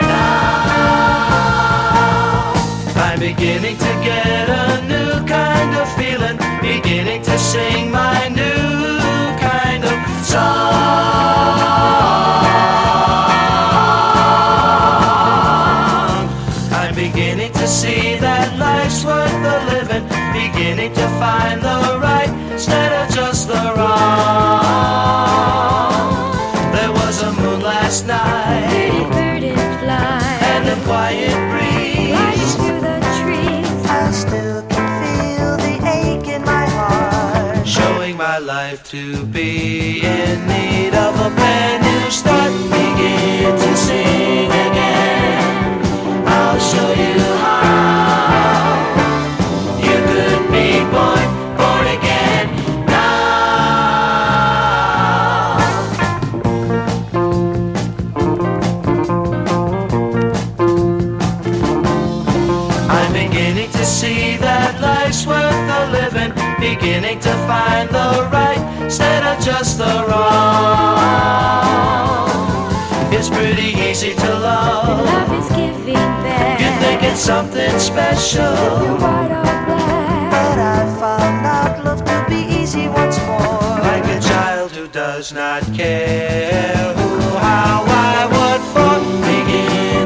スレあるため試聴でご確認ください